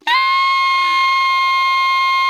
Index of /90_sSampleCDs/Giga Samples Collection/Sax/ALTO 3-WAY
ALTO GR C 5.wav